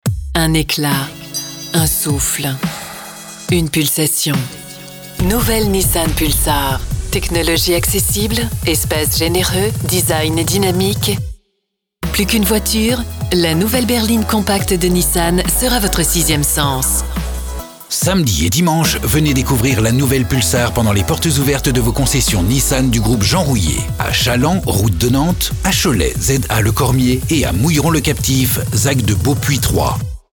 French female professional voice over Medium voice, warm and class but also neutral, corporate and smily
Sprechprobe: Werbung (Muttersprache):